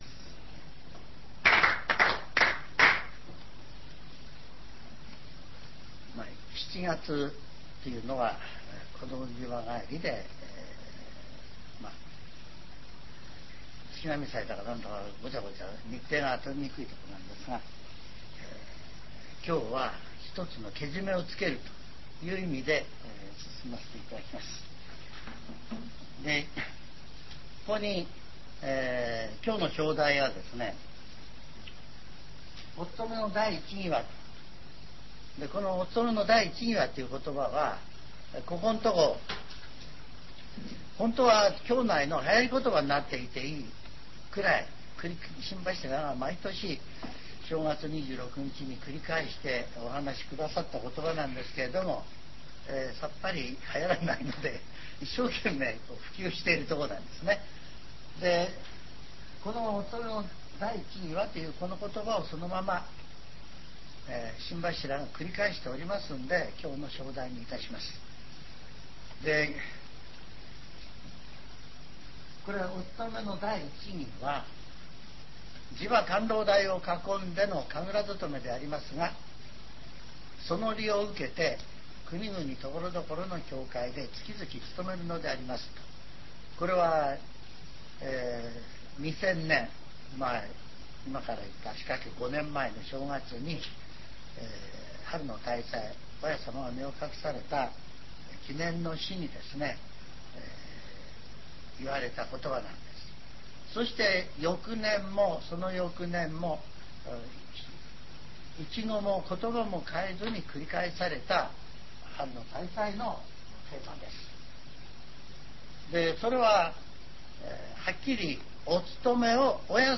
全70曲中35曲目 ジャンル: Speech